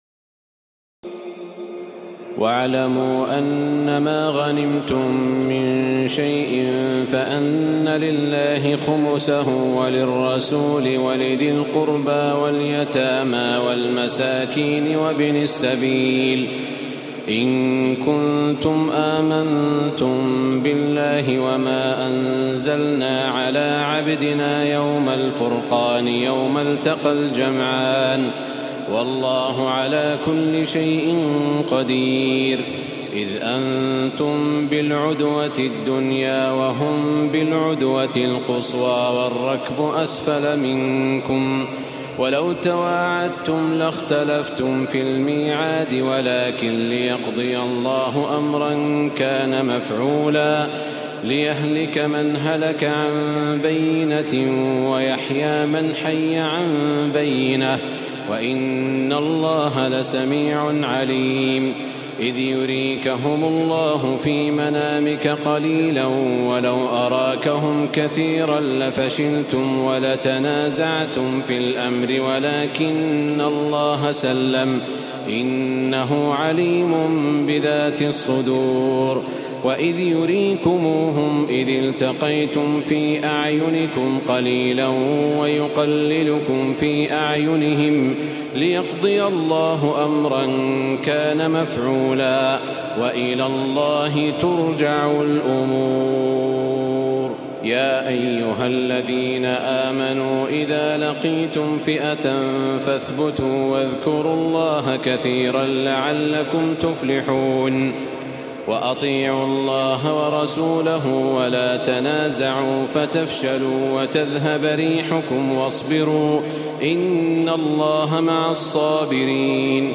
تراويح الليلة العاشرة رمضان 1435هـ من سورتي الأنفال (41-75) و التوبة (1-33) Taraweeh 10 st night Ramadan 1435H from Surah Al-Anfal and At-Tawba > تراويح الحرم المكي عام 1435 🕋 > التراويح - تلاوات الحرمين